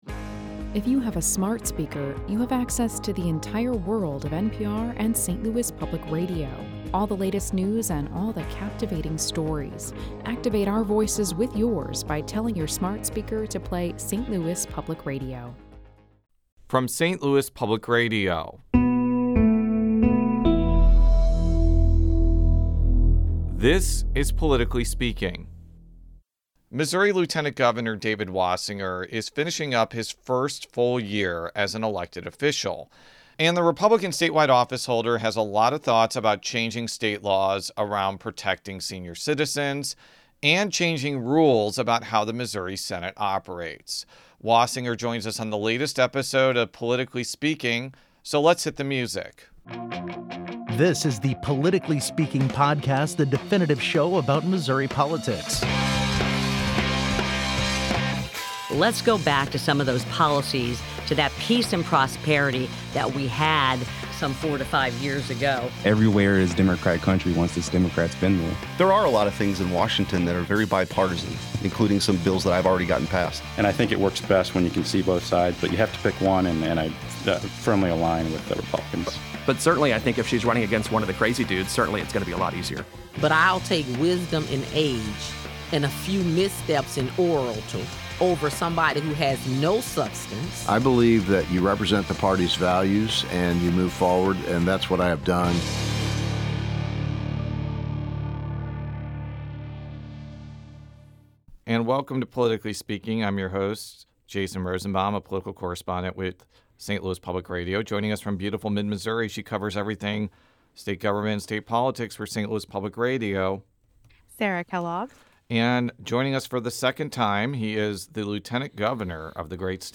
Missouri's political news makers talk candidly